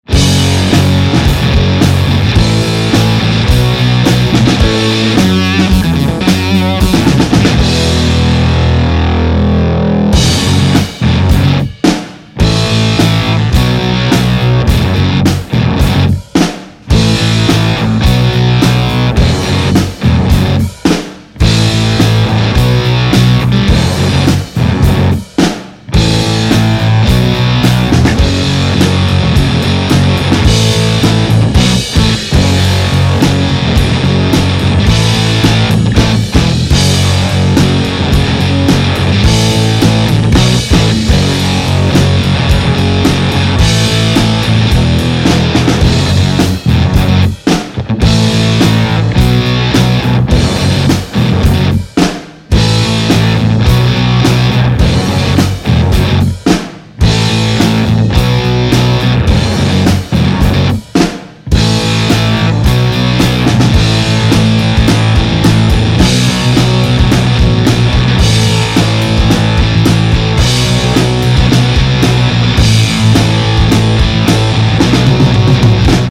I think you'll be blown away by how big it sounds in the context of a band!!!
The rhythm section was recorded full out balls to the wall!! 2 watts!!
Change your mind - octafuzz into TriFly into 212 Jensen blackbirds